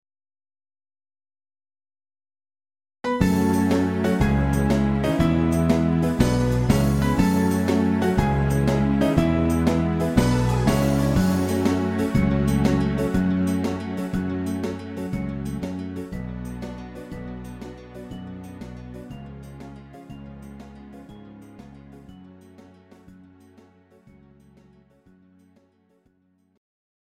Greek Ballad